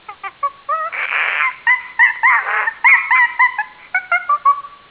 monkey.wav